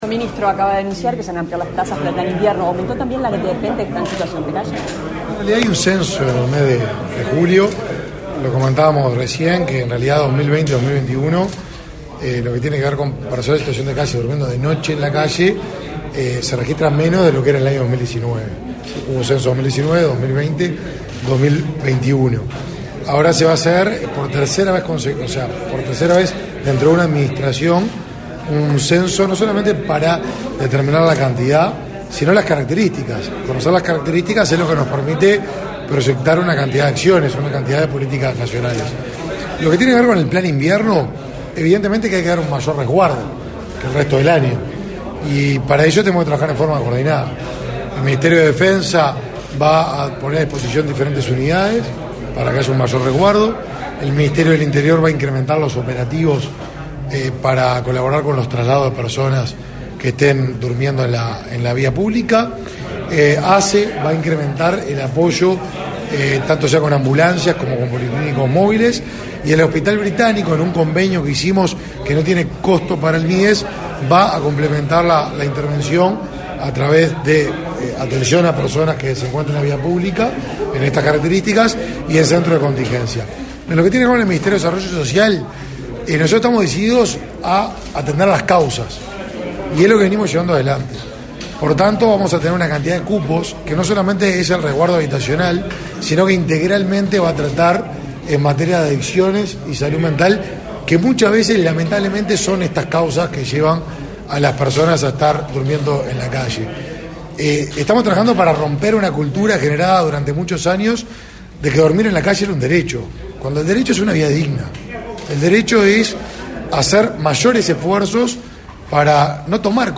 Declaraciones a la prensa del ministro de Desarrollo Social, Martín Lema
Declaraciones a la prensa del ministro de Desarrollo Social, Martín Lema 15/05/2023 Compartir Facebook X Copiar enlace WhatsApp LinkedIn Tras participar en la reunión de coordinación de acciones por el Plan Nacional de Invierno, este 15 de mayo, el ministro Martín Lema realizó declaraciones a la prensa.